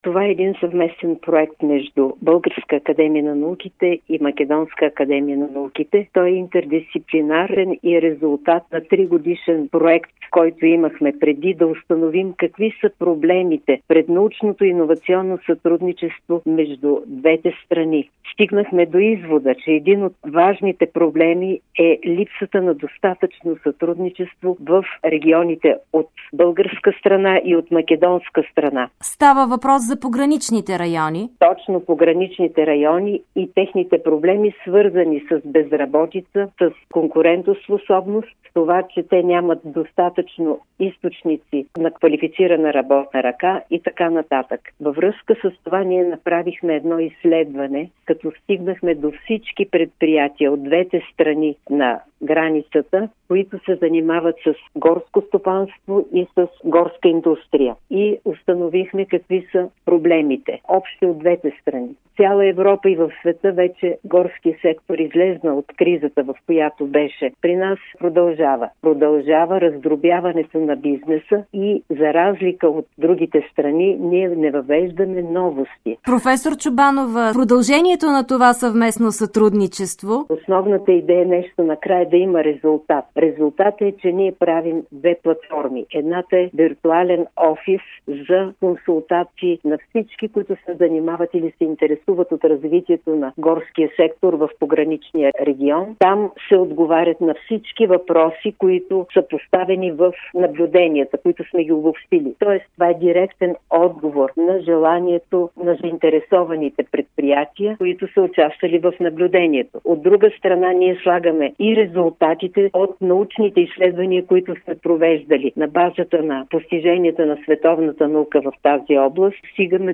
Интервю
ПЪЛЕН ЗАПИС НА ИНТЕРВЮТО